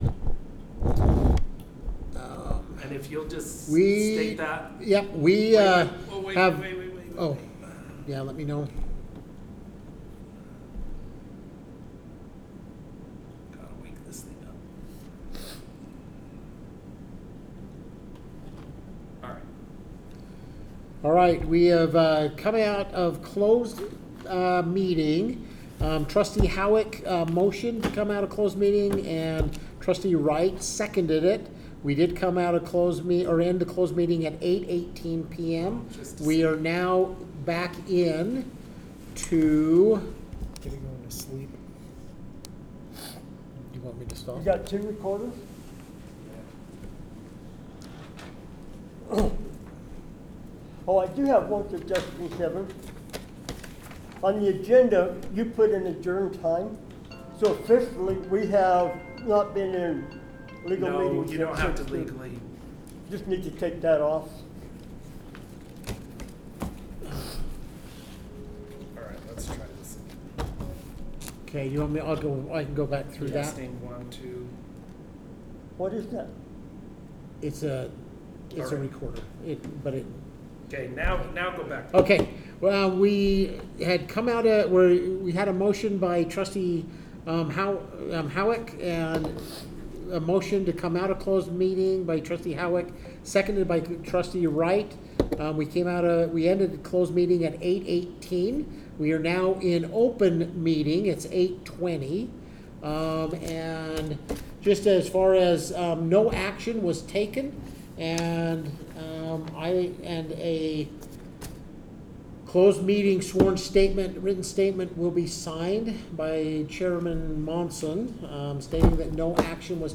The Board generally meets on the third Wednesday of each month at 4:30 PM in the Element Event Center.
Board Meeting